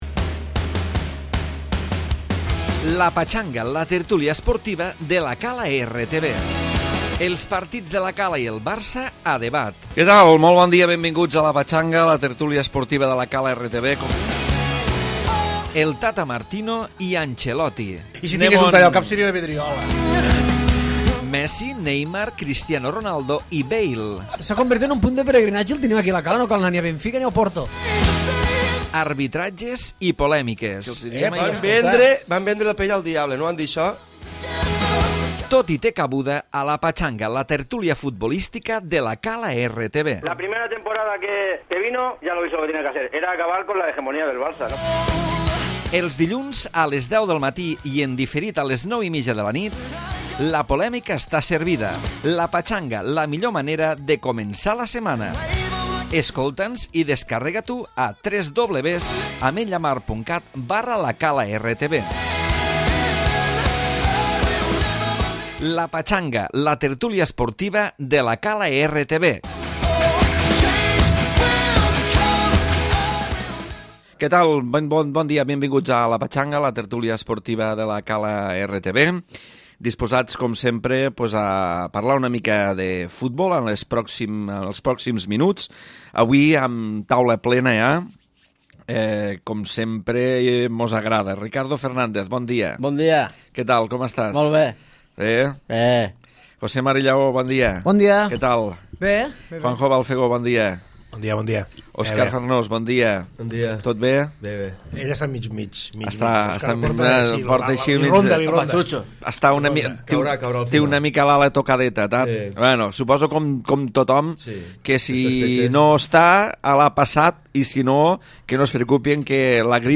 Tertúlia d'actualitat esportiva